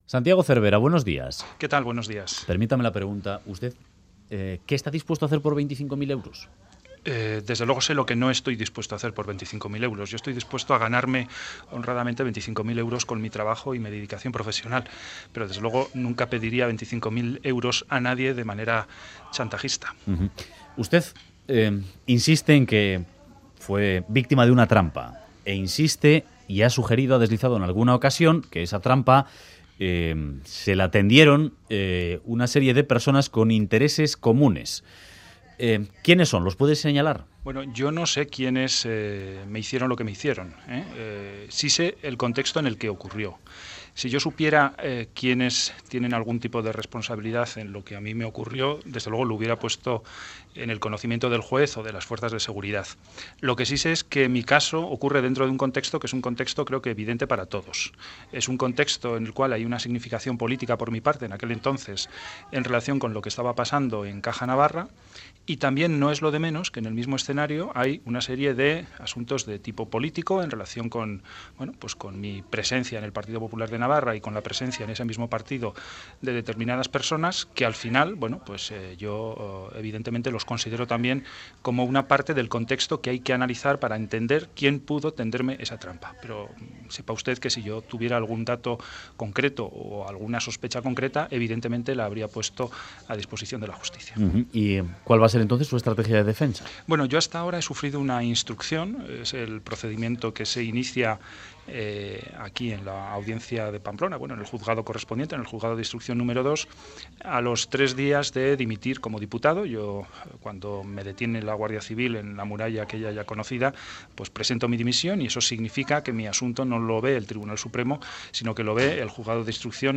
Radio Euskadi BOULEVARD Santiago Cervera: 'Lo de Caja Navarra fue un expolio social' Última actualización: 21/10/2014 09:35 (UTC+2) En entrevista al Boulevard de Radio Euskadi, el exdiputado del PP, Santiago Cervera, ha insistido en negar su relación con un presunto chantaje al expresidente de Caja Navarra, y ha apuntado que lo ocurrido con esa entidad fue un expolio social.